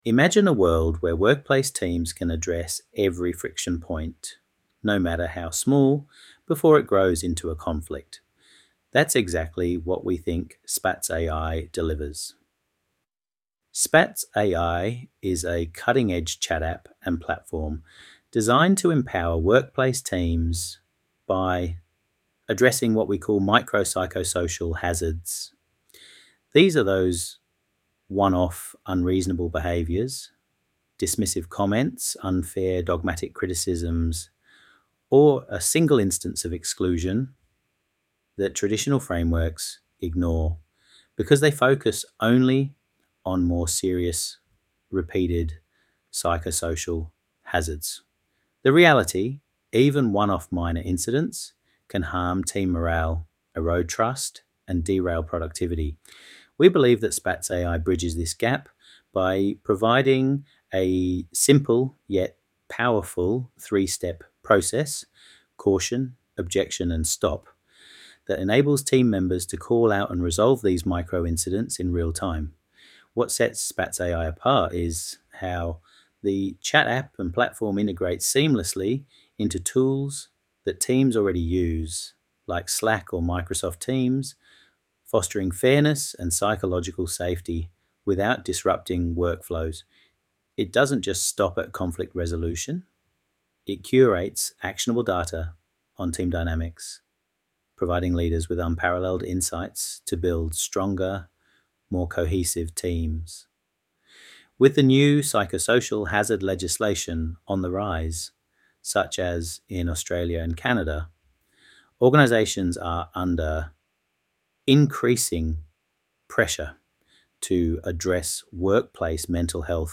The Latest SpatzAI 2 Minute Pitch